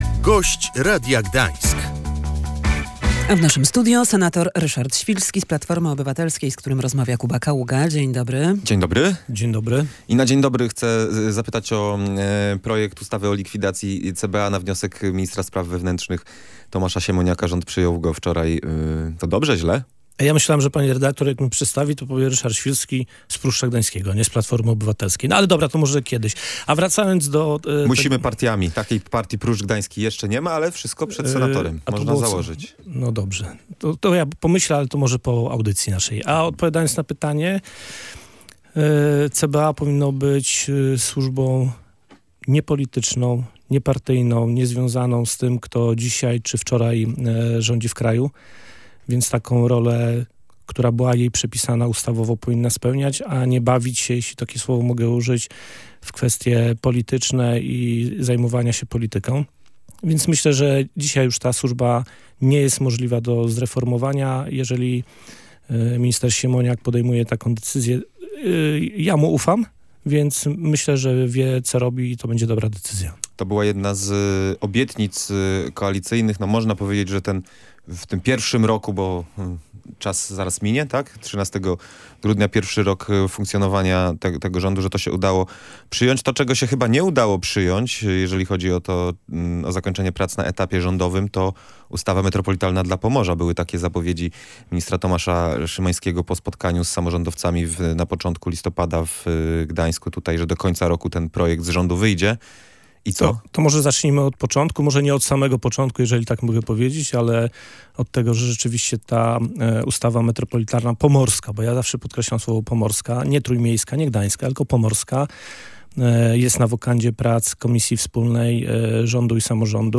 Na początku roku będzie gotowa ustawa metropolitalna dla Pomorza – zapewniał w Radiu Gdańsk senator Koalicji Obywatelskiej Ryszard Świlski.